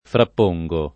vai all'elenco alfabetico delle voci ingrandisci il carattere 100% rimpicciolisci il carattere stampa invia tramite posta elettronica codividi su Facebook frapporre [ frapp 1 rre ] v.; frappongo [ frapp 1jg o ], ‑ni — coniug. come porre